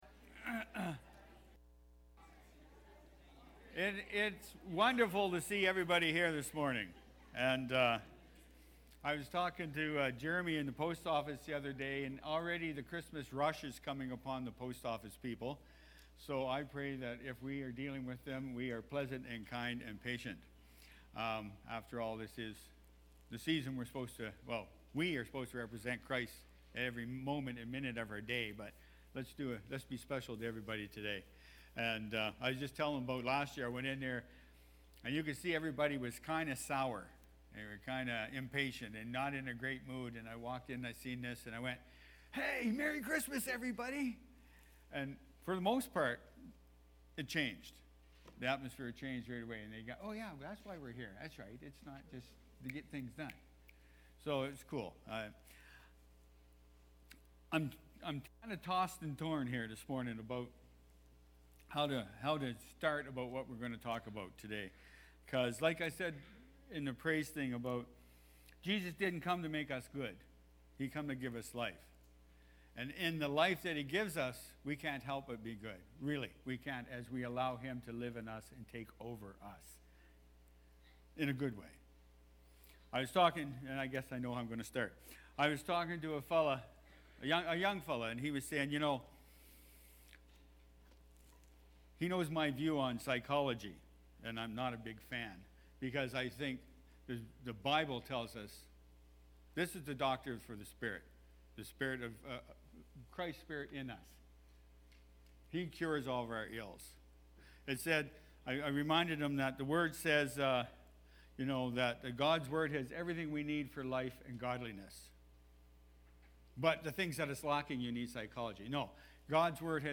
Dec-3-2023-sermon-audio.mp3